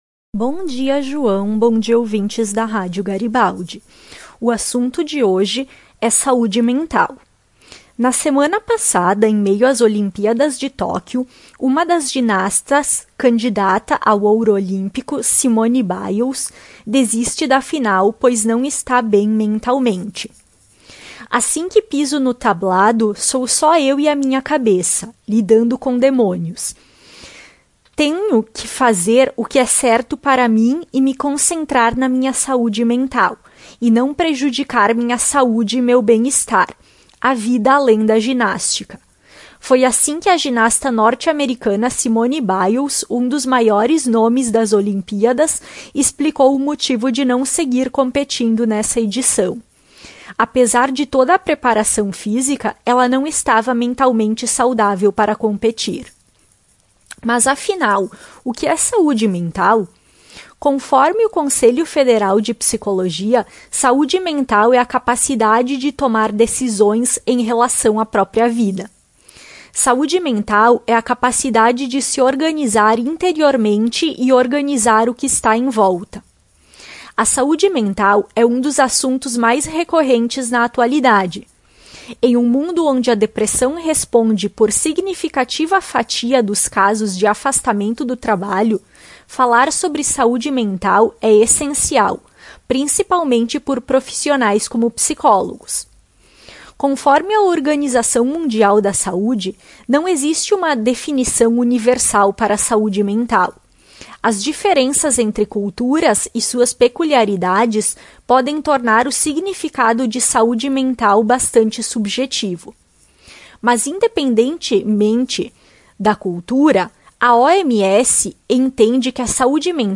Psicóloga fala da ginasta que desistiu da prova por não estar bem com sua saúde mental